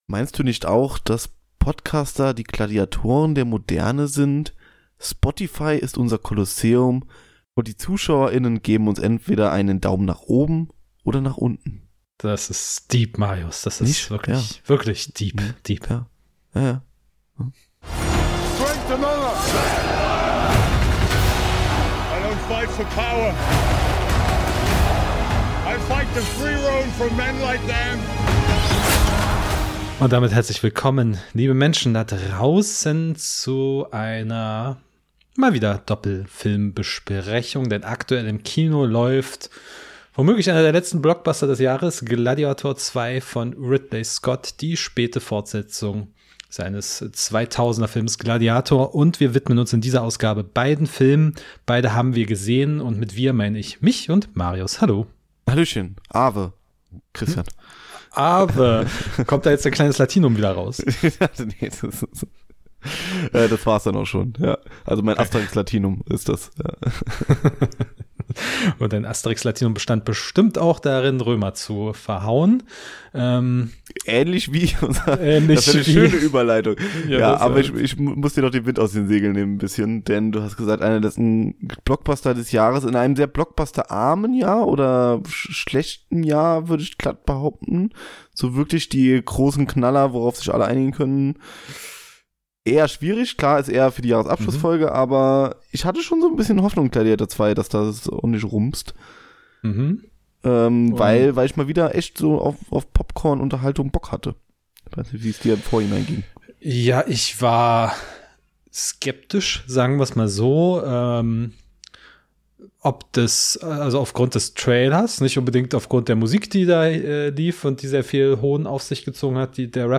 Gladiator 1 & 2 | Doppel-Review-Talk ~ Die Kinotagesstätte Podcast